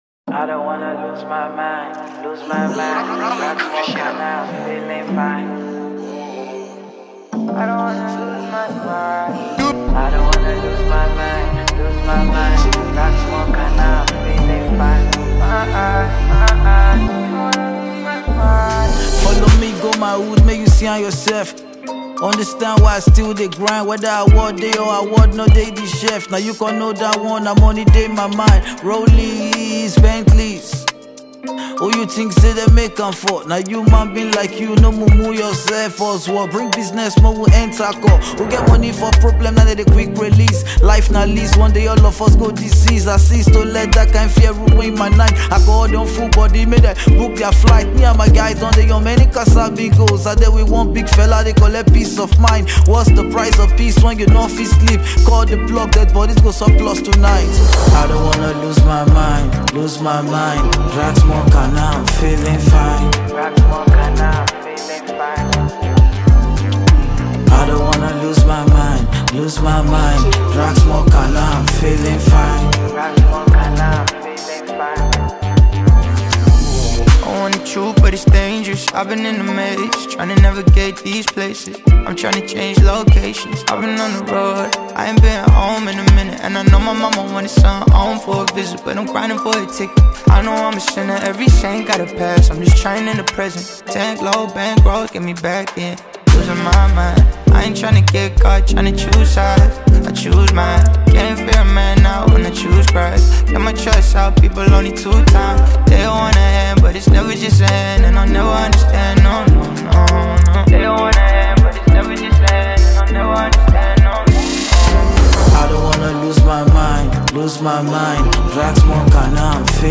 Nigerian rapper and songwriter